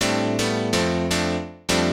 PIANO006_VOCAL_125_A_SC3(R).wav